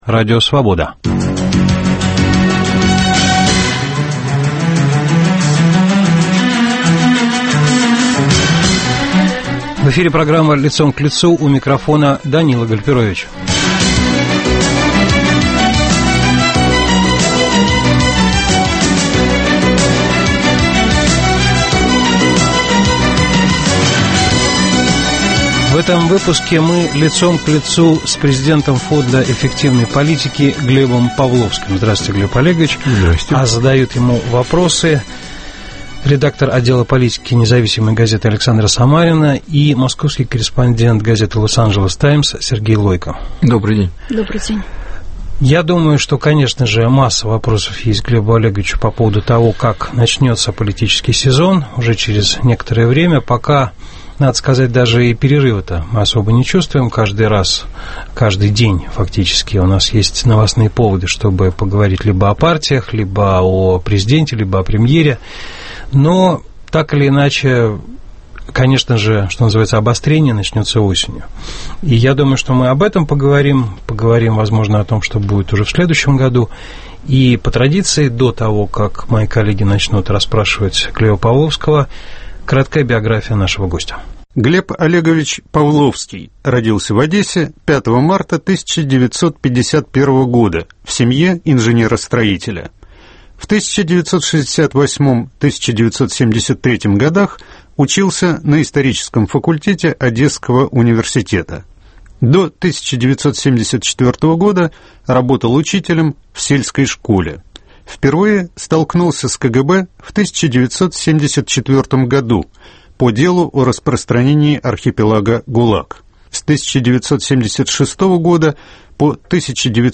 В программе - президент Фонда эффективной политики Глеб Павловский.